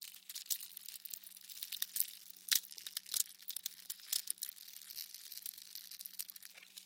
Звуки божьей коровки
Божья коровка шагает по сухим листьям